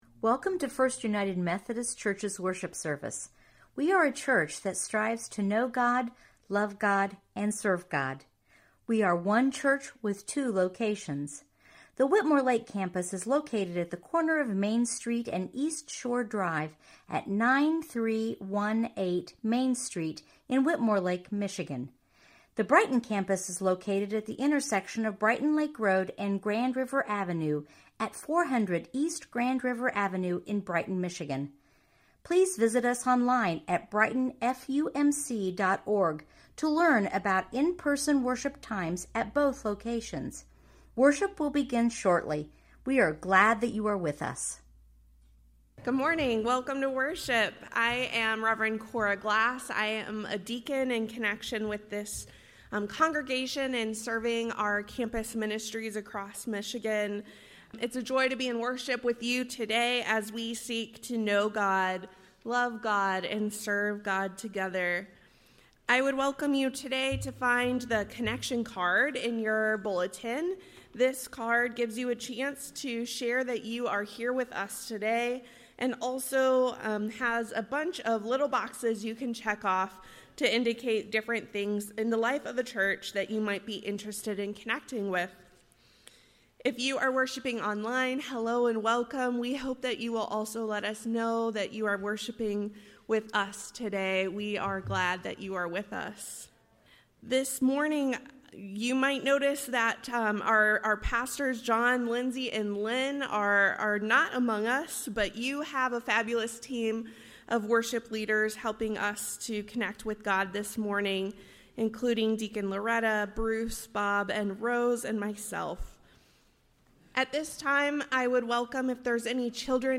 Certified Lay Speaker